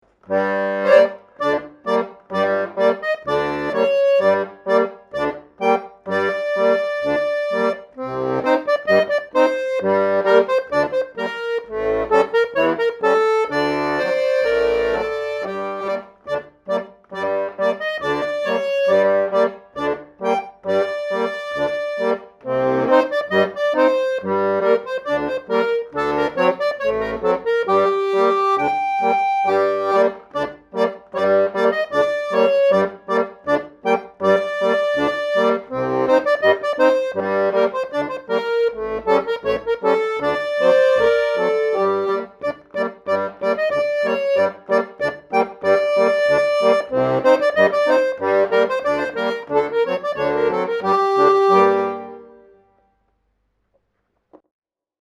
leichtes Stück im russisch-jüdischen Stil